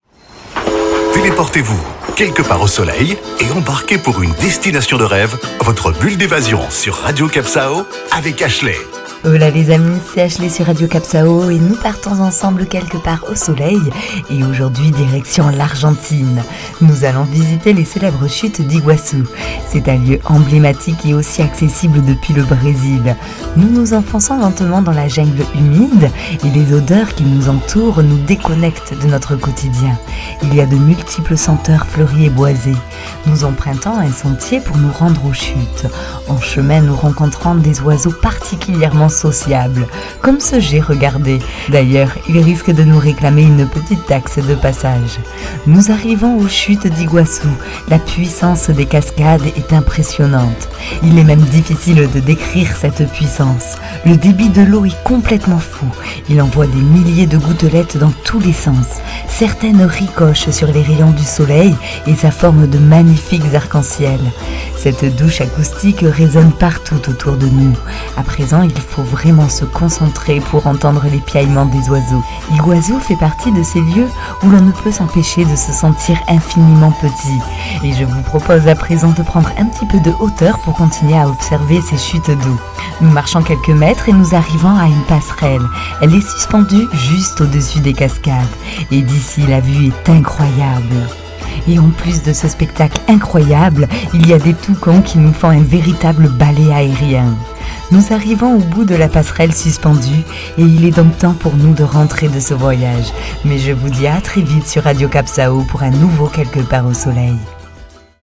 Carte postale sonore : à la frontière entre le Brésil et l'Argentine, laissez-vous emporter par la puissance des cascades devant vous, en respirant les senteurs fleuries et boisées et e écoutant le piaillement des gets et toucans.